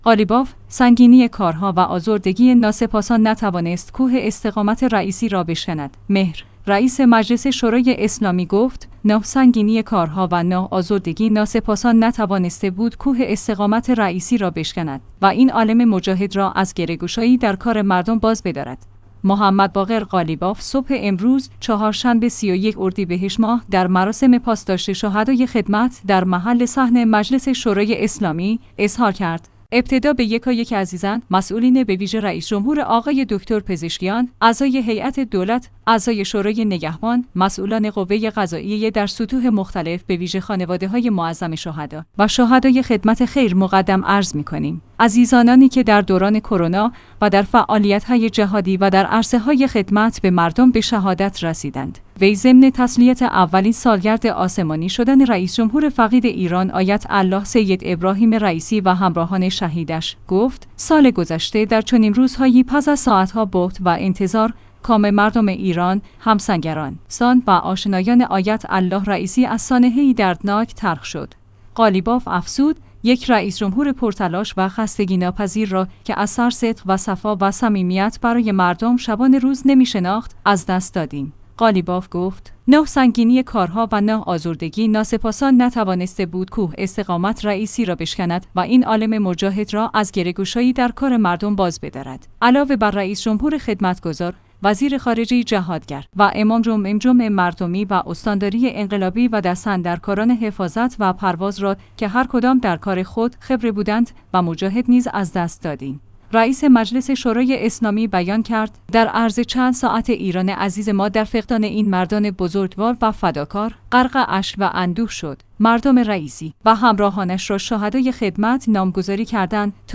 محمدباقر قالیباف صبح امروز (چهارشنبه ۳۱ اردیبهشت ماه) در مراسم پاسداشت شهدای خدمت در محل صحن مجلس شورای اسلامی، اظهار کرد: ابتدا به یک